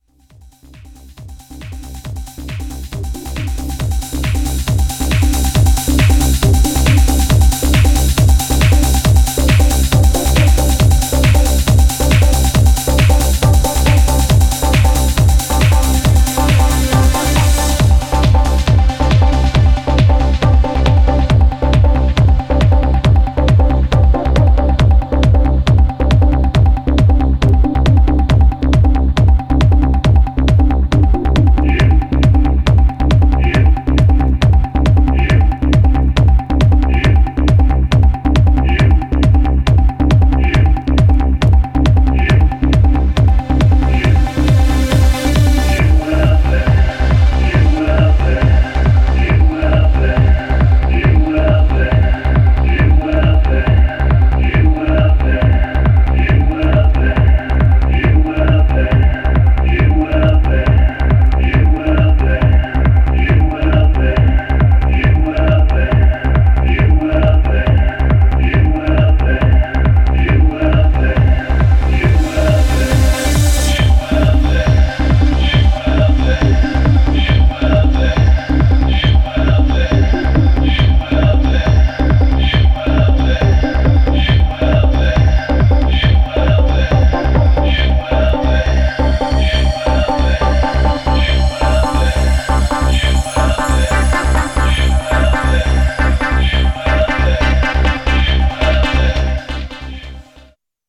Styl: Progressive, House, Techno, Trance